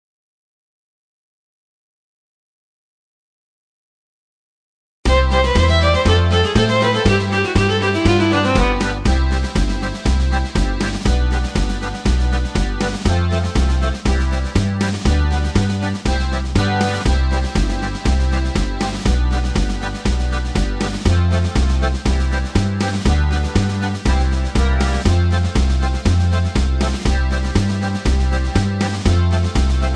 Key-C
Tags: backing tracks , irish songs , karaoke , sound tracks